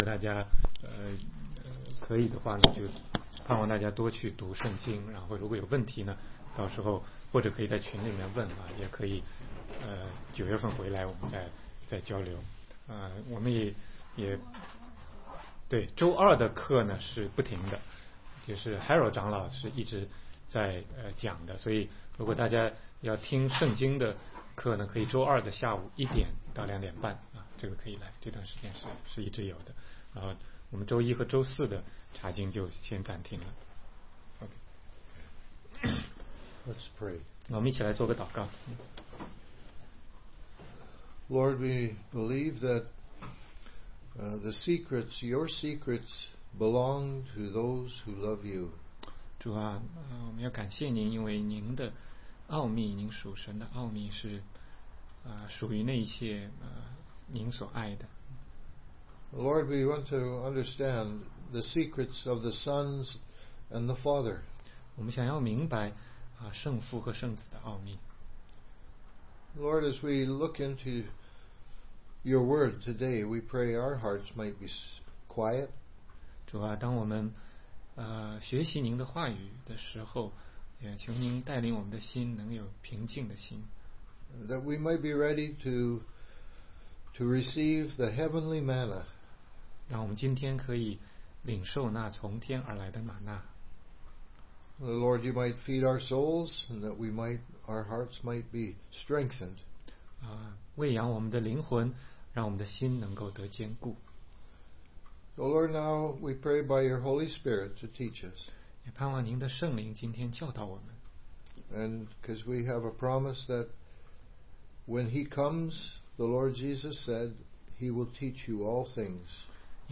16街讲道录音 - 约翰福音7章19-24节